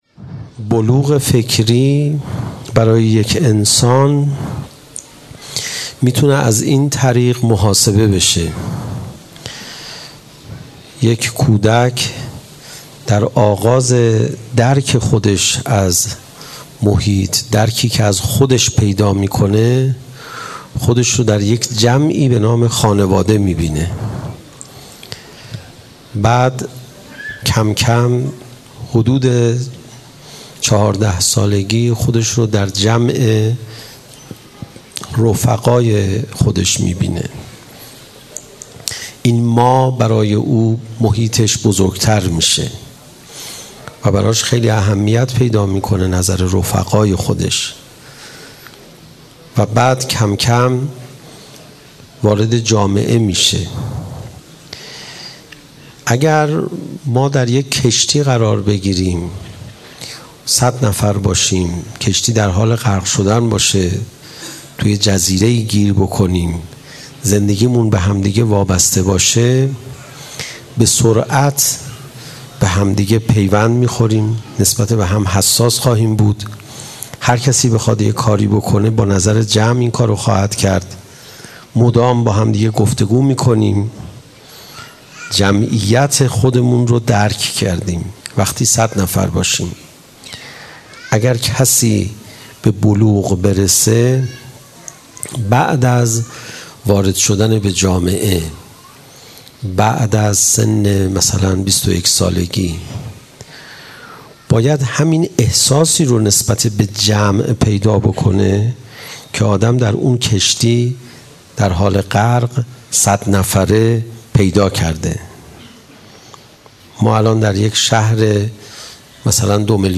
آنچه پیش رو دارید سومین جلسه سخنرانی آیت الله علیرضا پناهیان است که در دهه دوم فاطمیه سال ۱۳۹۸در هیأت ثارالله قم برگزار شده است.
sokhanrani.mp3